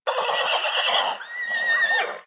Relincho de un caballo